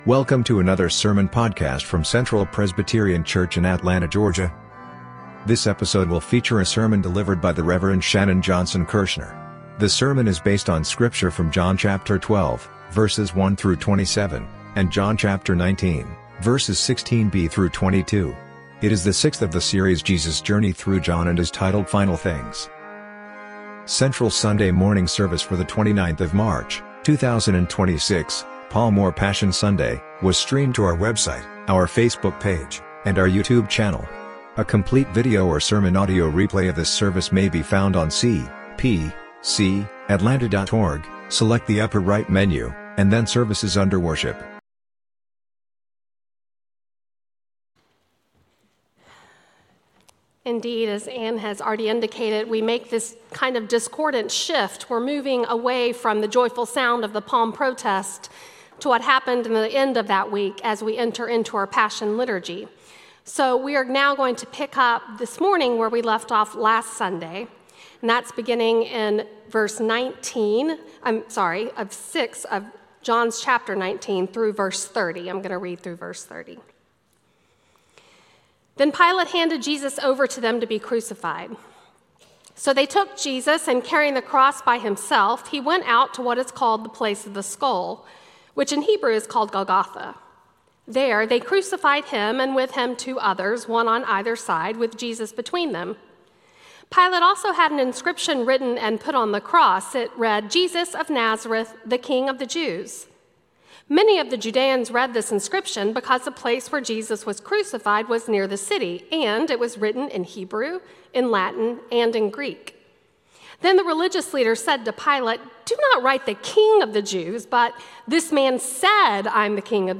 Sermon Audio: